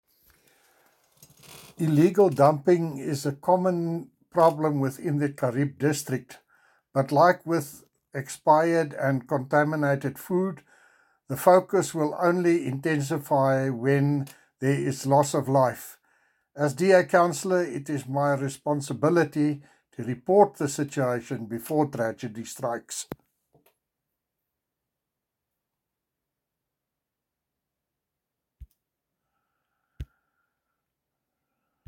Afrikaans soundbites by Cllr Jacques van Rensburg and